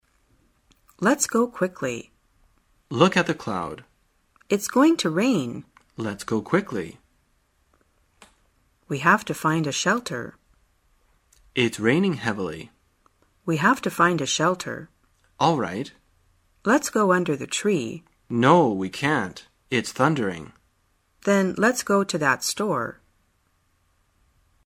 真人发音配字幕帮助英语爱好者们练习听力并进行口语跟读。